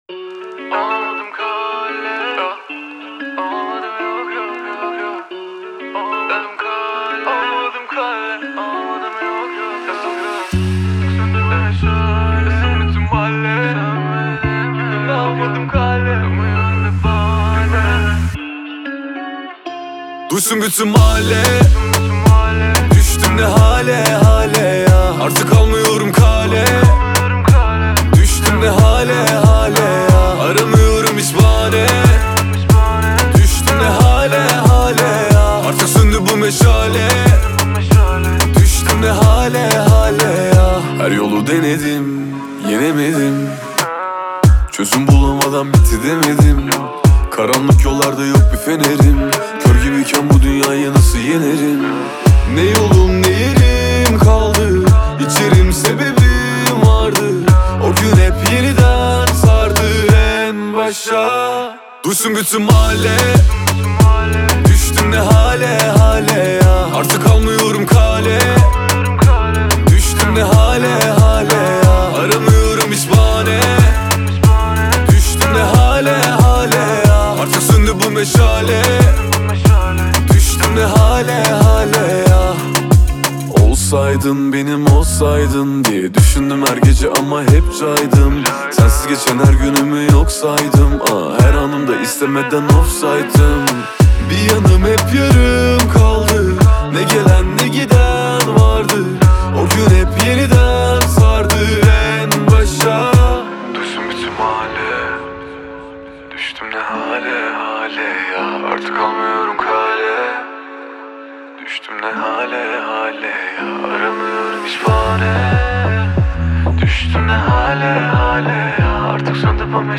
Песня наполнена меланхоличными мелодиями и мягким вокалом